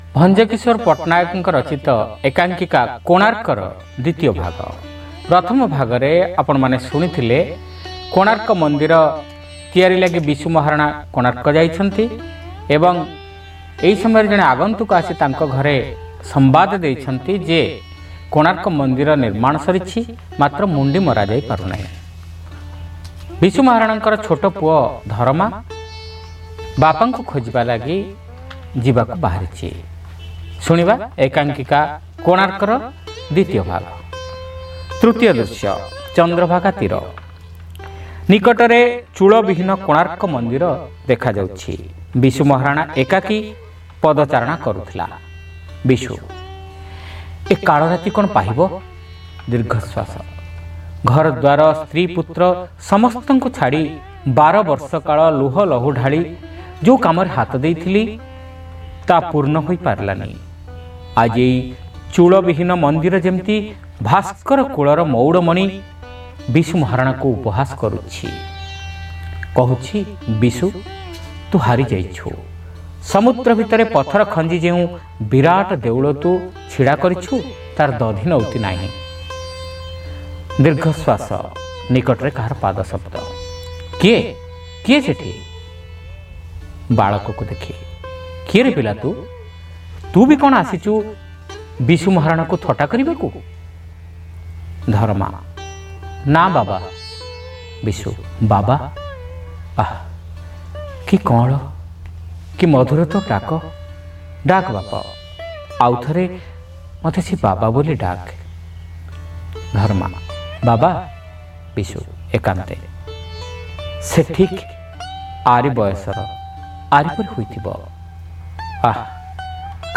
ଶ୍ରାବ୍ୟ ଏକାଙ୍କିକା : କୋଣାର୍କ (ଦ୍ୱିତୀୟ ଭାଗ)